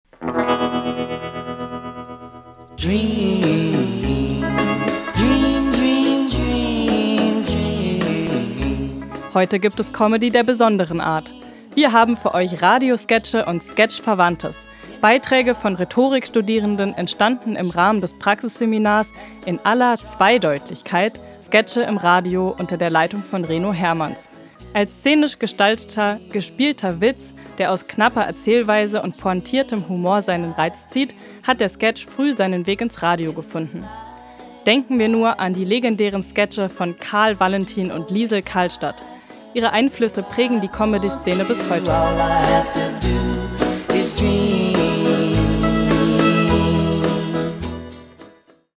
Radiosketche und Sketch-Verwandtes (647)